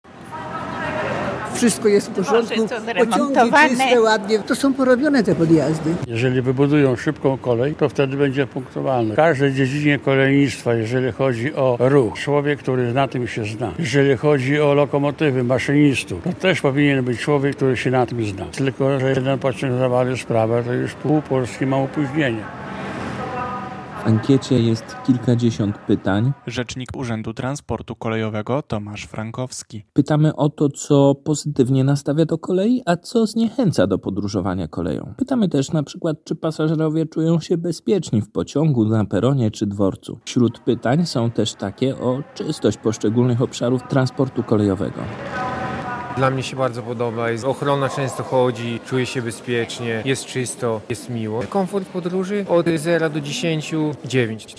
Jak białostoczanie oceniają podróżowanie koleją - relacja